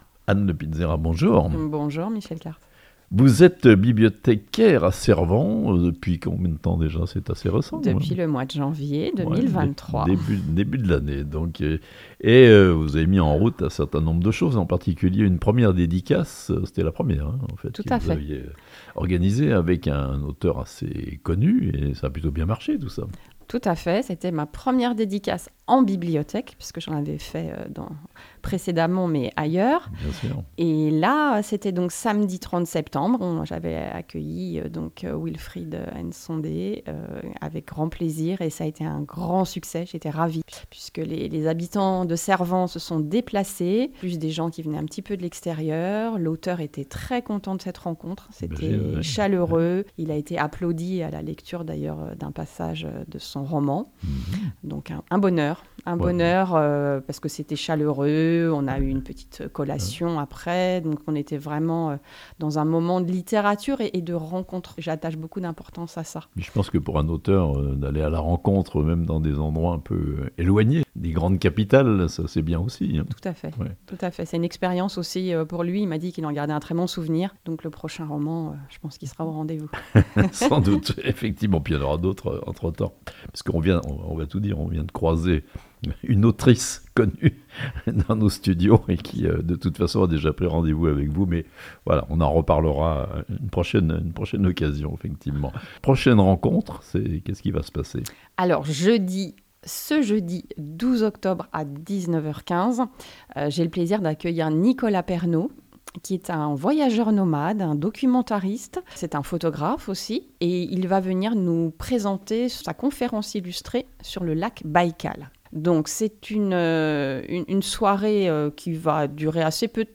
Une conférence illustrée sur le lac Baïkal à la bibliothèque de Cervens le jeudi 12 octobre (interview)